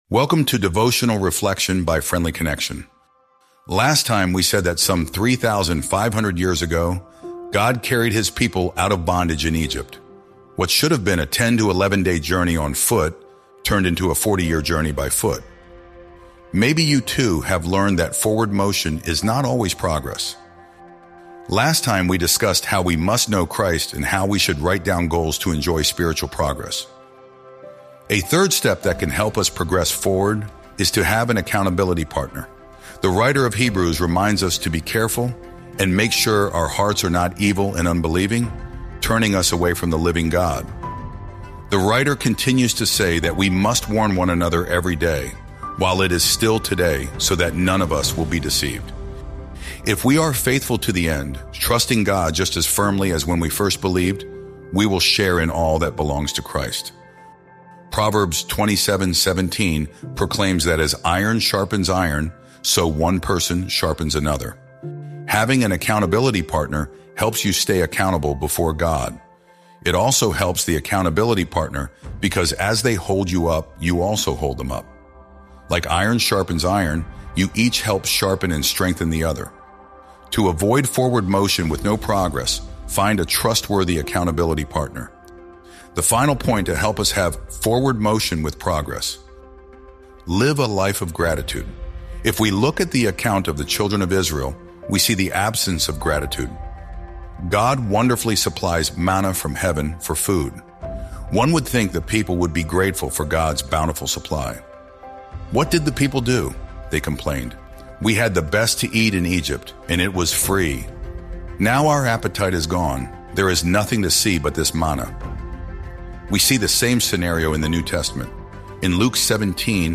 Sermons | Honey Creek New Providence Friends Church